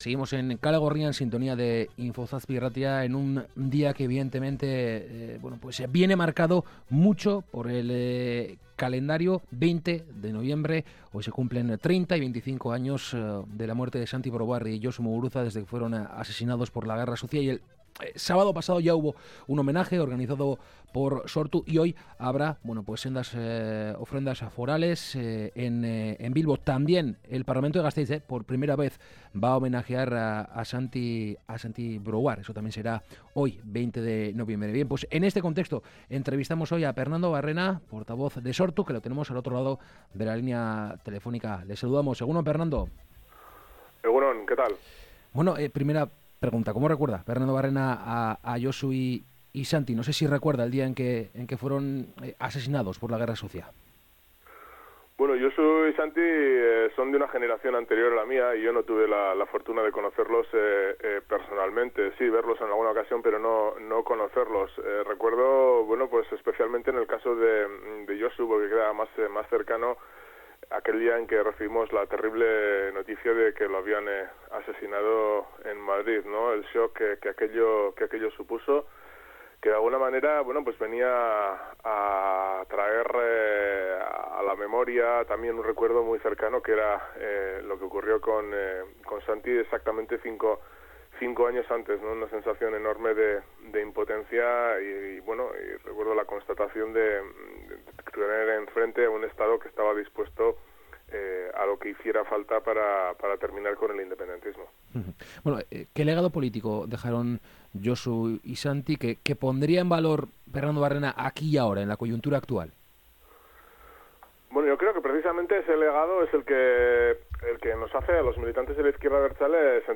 Esta mañana en Kalegorrian hemos charlado con Pernando Barrena, portavoz de Sortu, y en un día tan señalado como el de hoy, 20 de noviembre, le hemos preguntado sobre los asesinatos de Santi Brouard y Josu Muguruza hace 30 y 25 años, respectivamente.